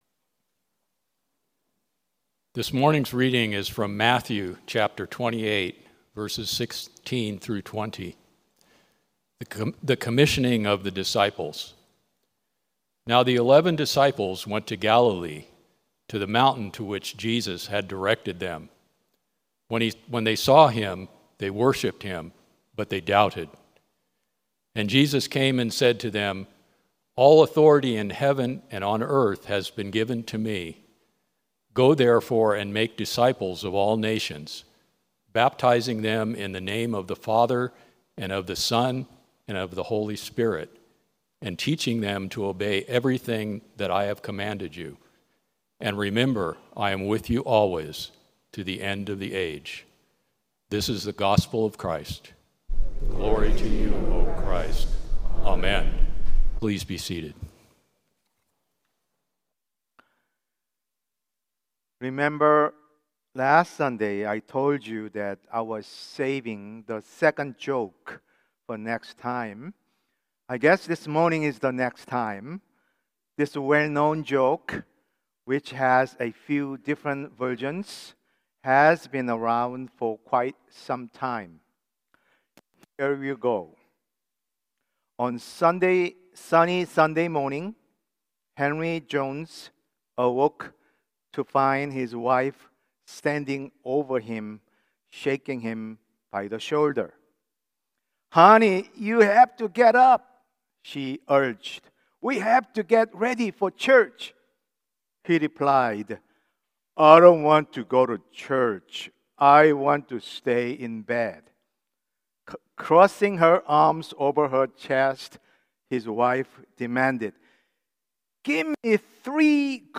Sermon – Methodist Church Riverside